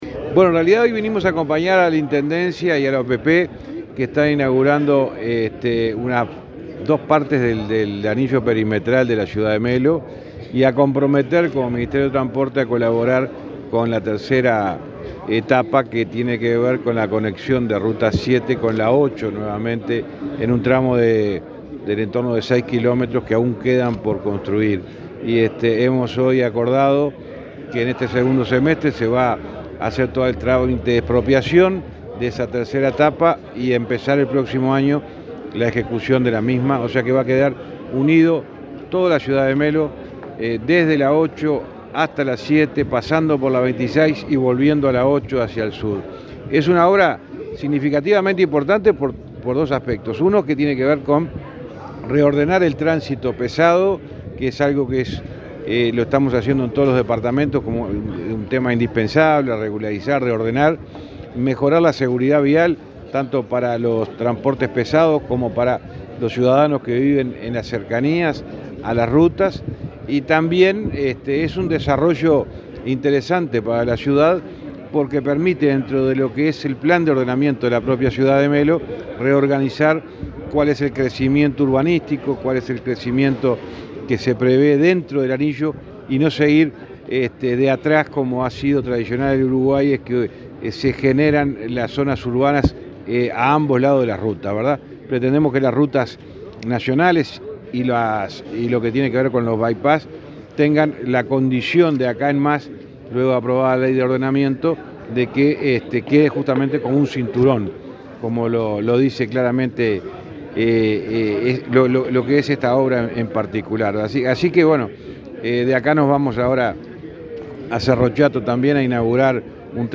Entrevista al ministro de Transporte, José Luis Falero